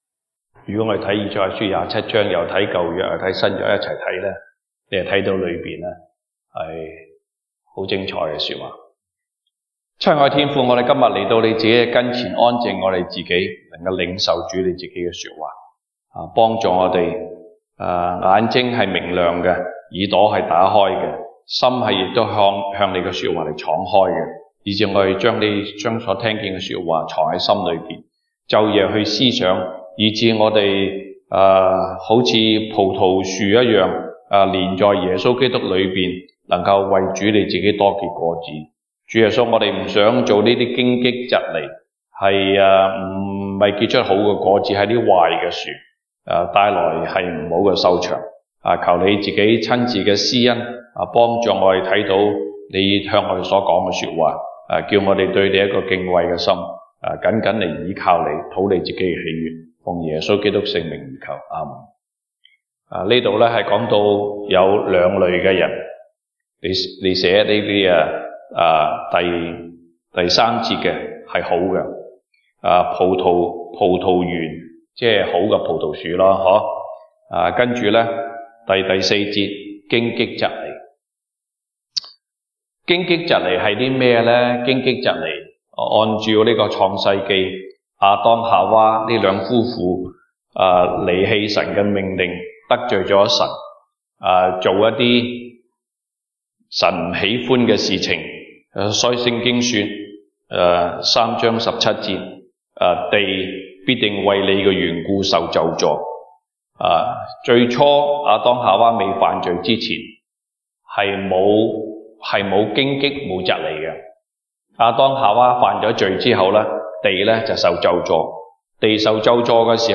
東北堂證道 (粵語) North Side: 到那日，主按照應許在地上施行拯救